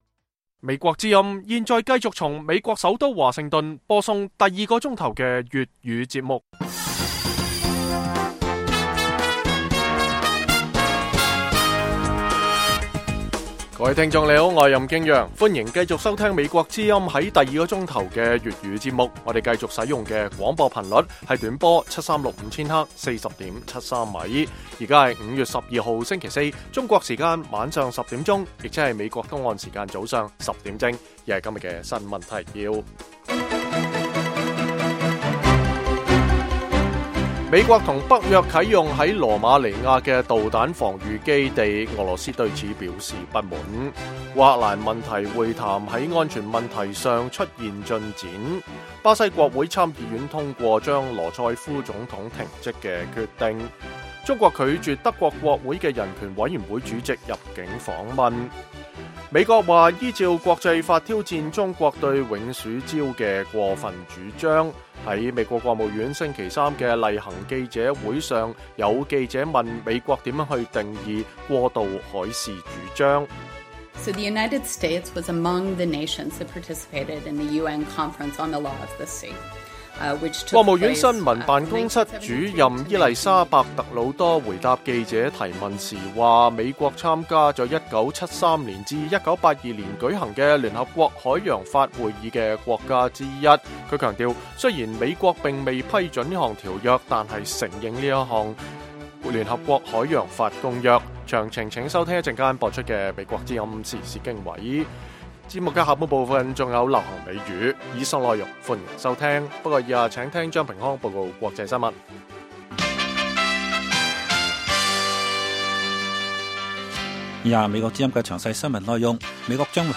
粵語新聞 晚上10-11點
北京時間每晚10－11點 (1400-1500 UTC)粵語廣播節目。內容包括國際新聞、時事經緯、英語教學和社論。